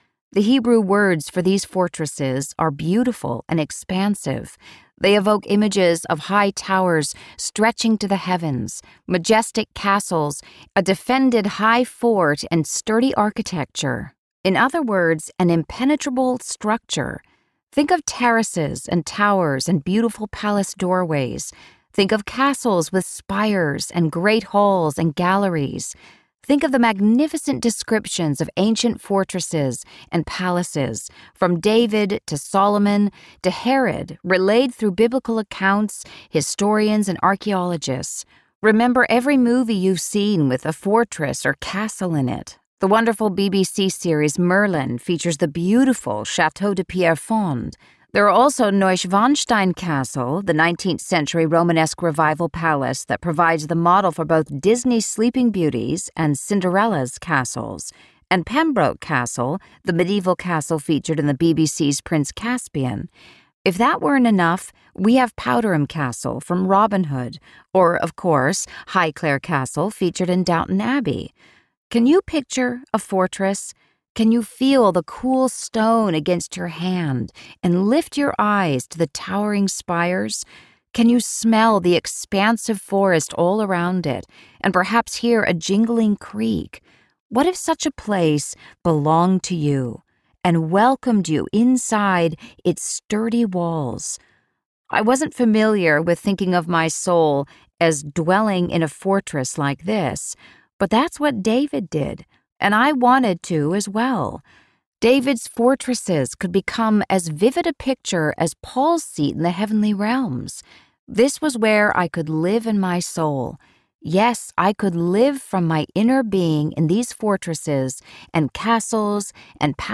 Guarded by Christ Audiobook
Narrator
4.98 Hrs. – Unabridged